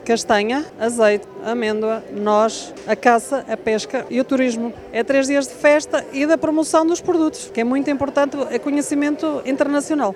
Também a presidente da Junta de Freguesia de Sambade, Covelas e Vila Nova, Maria Fernandes, destacou as várias áreas representadas no certame, com o objetivo de promover o território e a sua identidade: